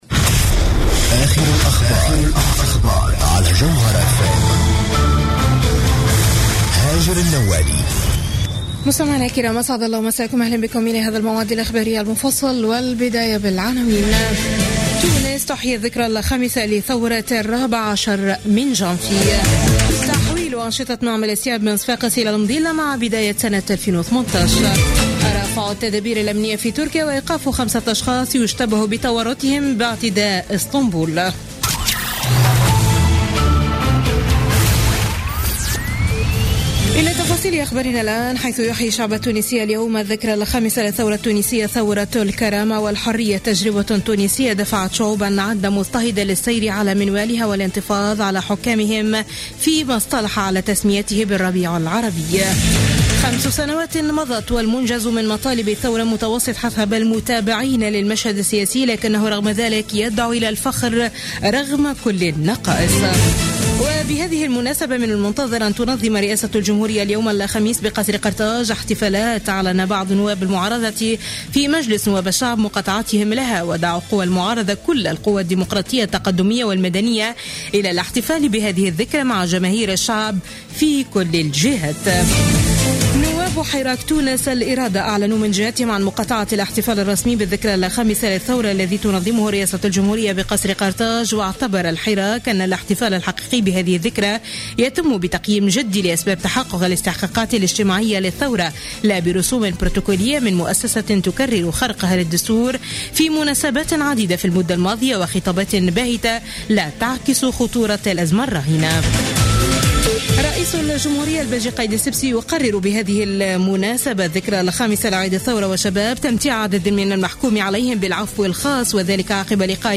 Journal Info 00:00 du Jeudi 14 Janvier 2016